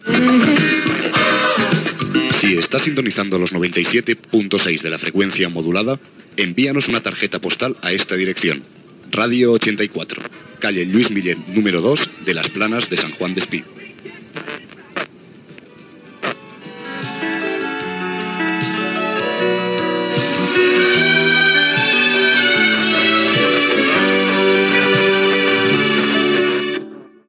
Identificació i adreça de la ràdio